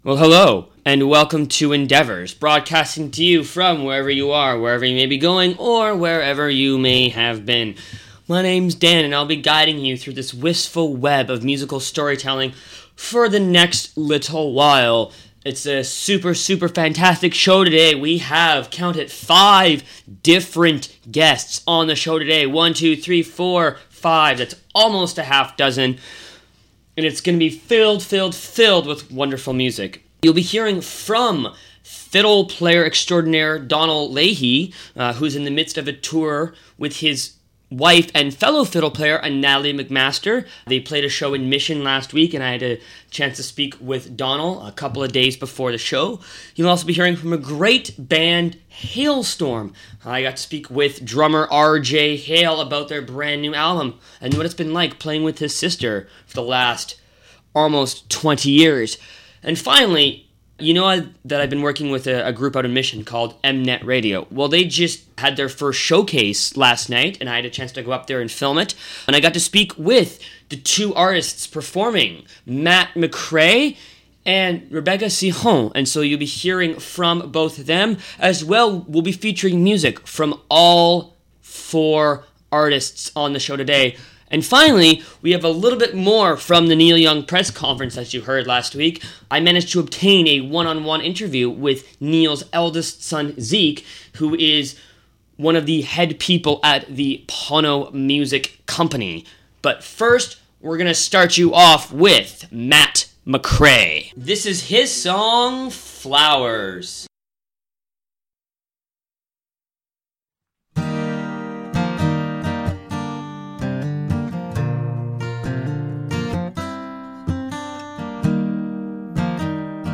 Endeavours - Live in Mission
Singer-songwriters